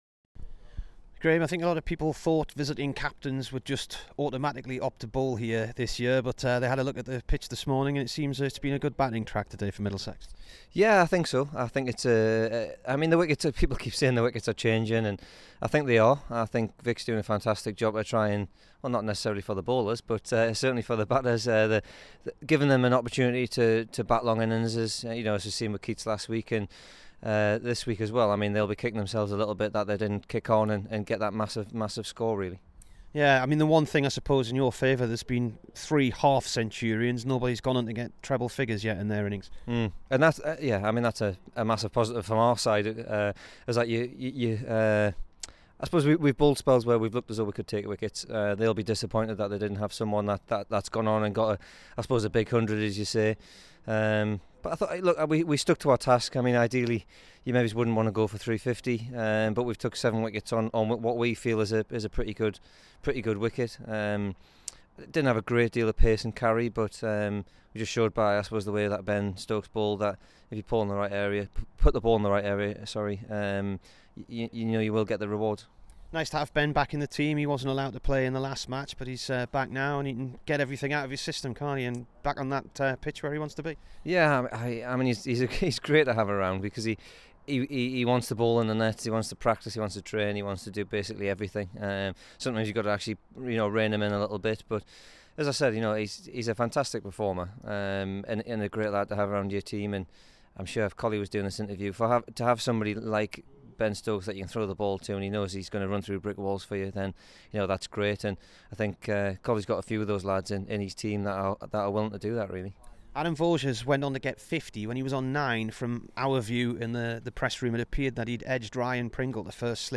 Here is the Durham bowler after day one v Middlesex.